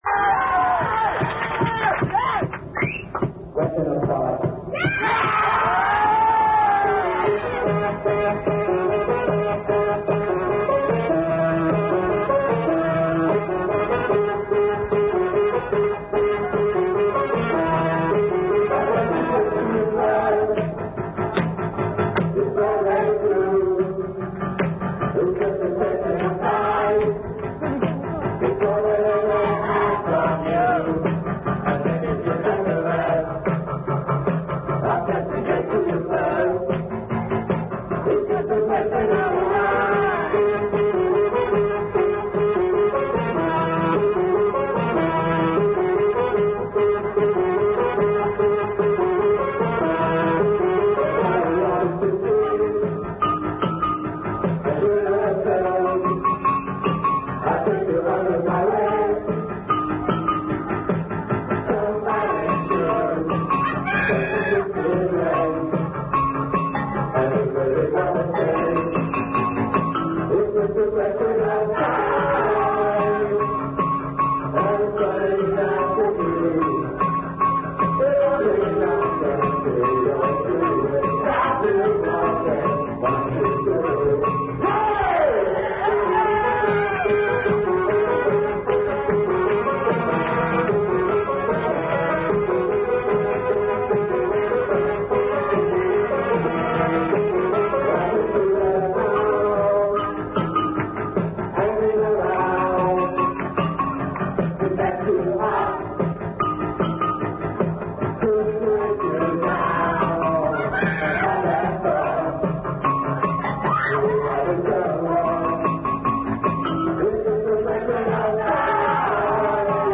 9.05.91.- France, Dunkerque